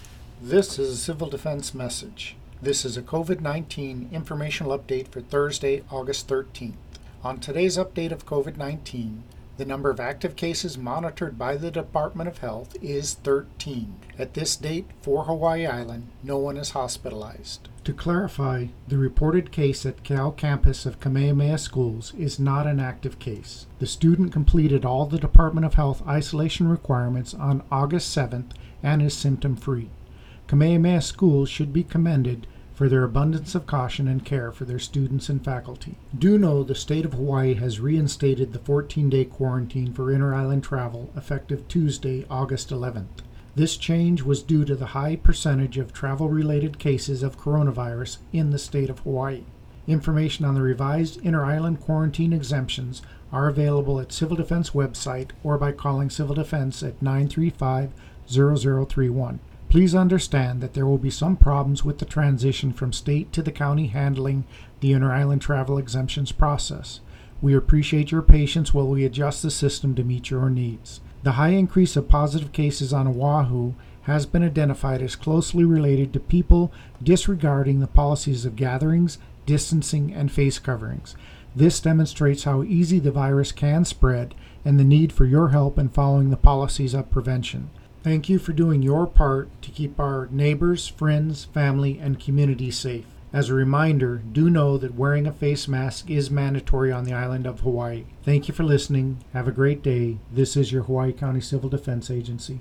Here is the full Hawaiʻi County Civil Defense radio message and statement text: